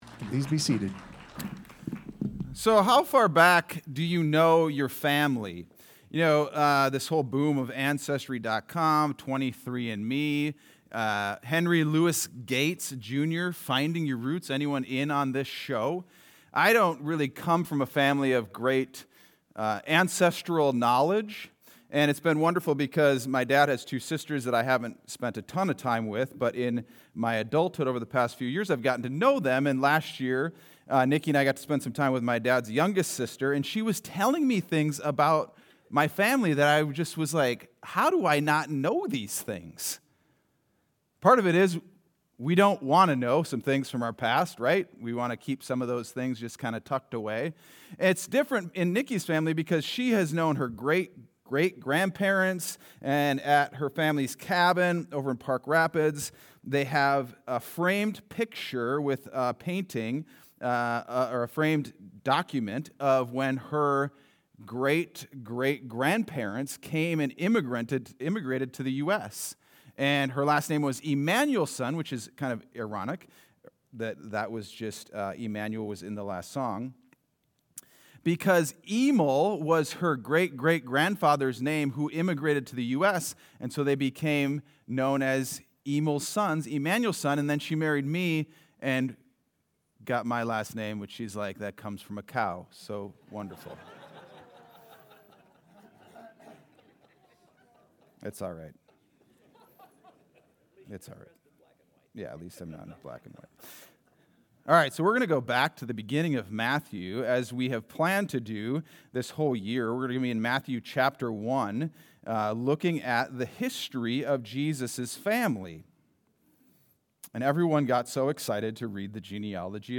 Sunday Sermon: 11-30-25